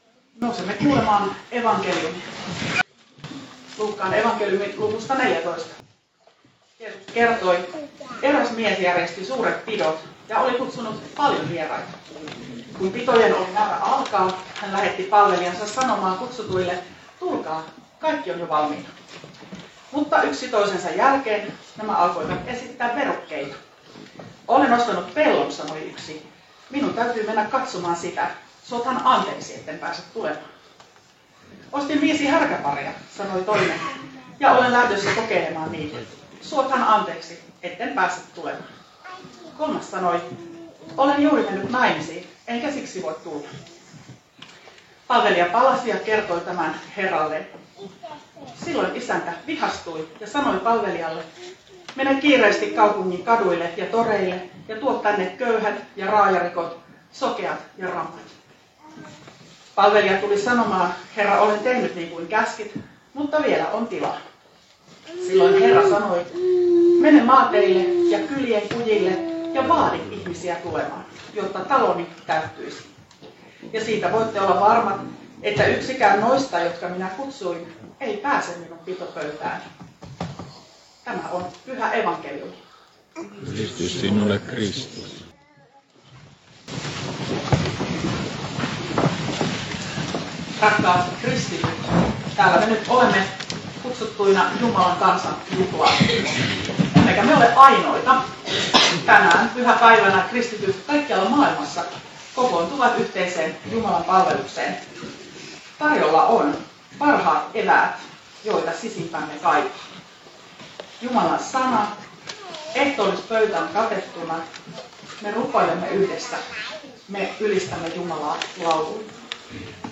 saarna Kannuksessa 3. sunnuntaina helluntaista Tekstinä Luuk. 14:16–24